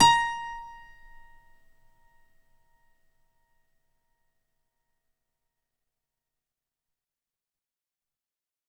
55ay-pno10-a#4.wav